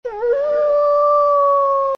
Download Fox sound effect for free.
Fox